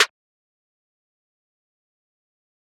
Clean Snare.wav